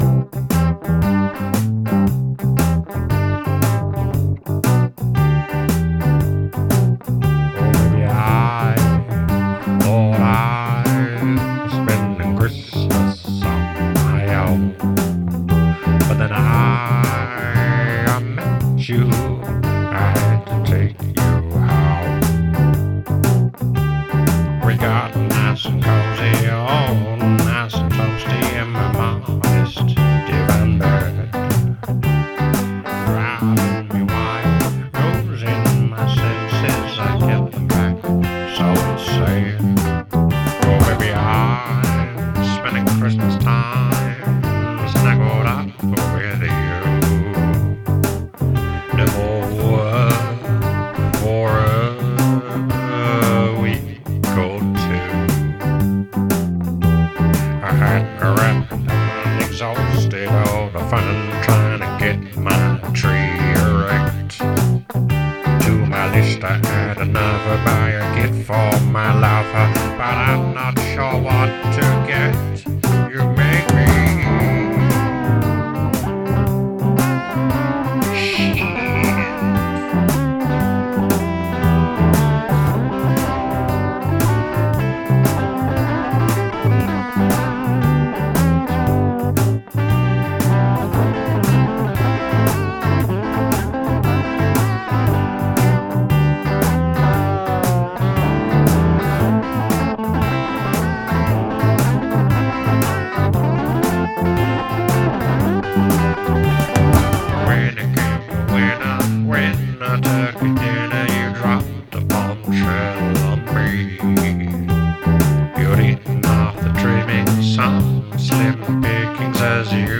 basement studio